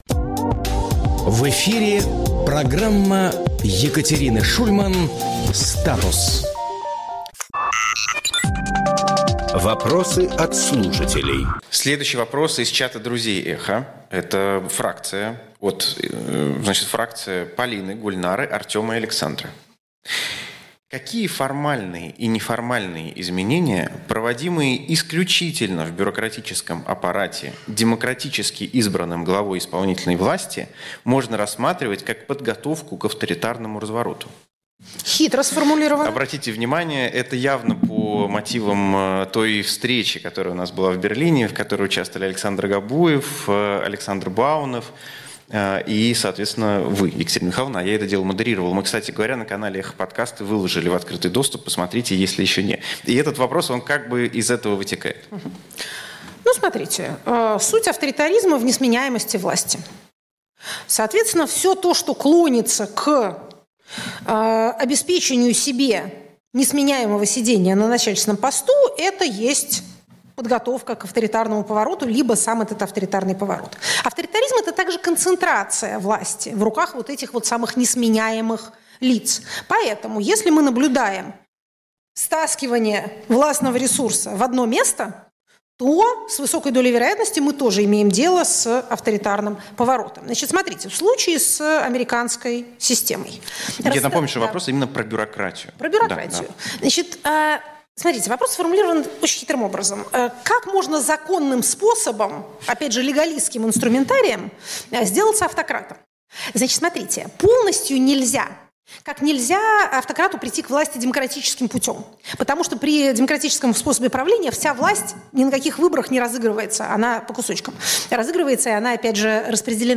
Екатерина Шульманполитолог
Фрагмент эфира от 25 марта.